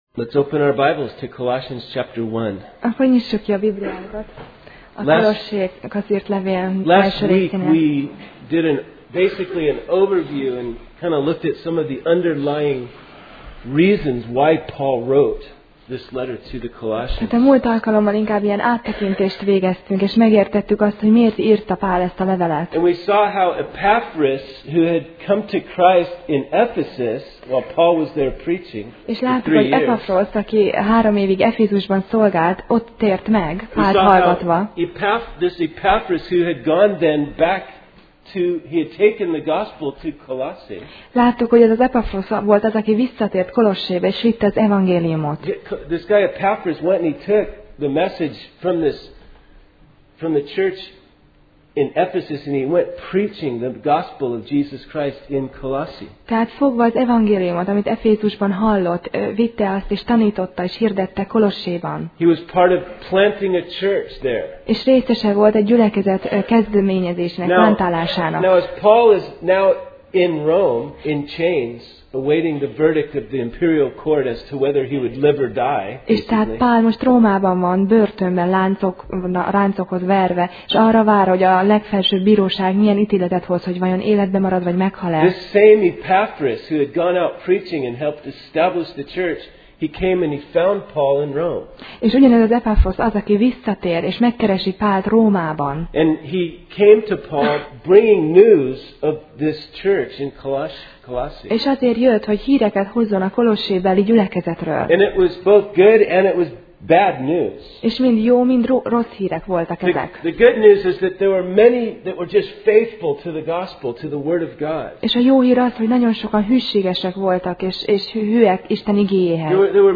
Kolossé Passage: Kolossé (Colossians) 1:6-8 Alkalom: Vasárnap Reggel